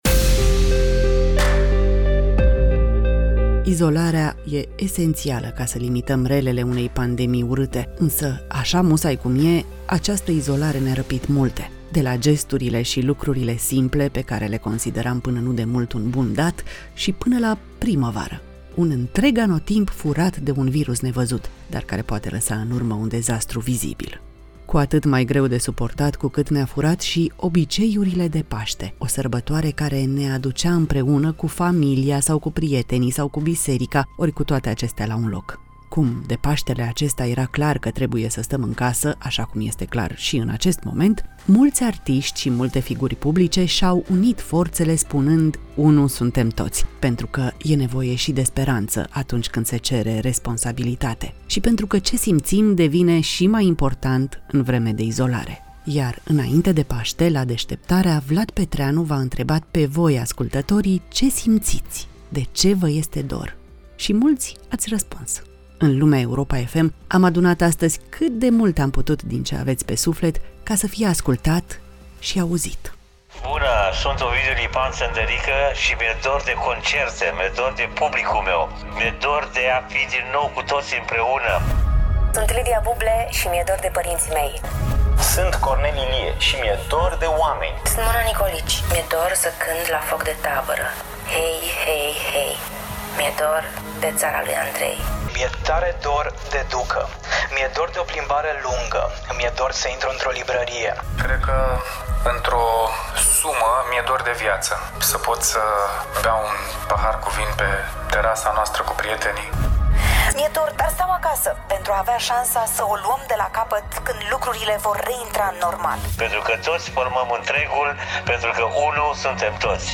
Lumea Europa FM: Vocile voastre, dorurile tuturor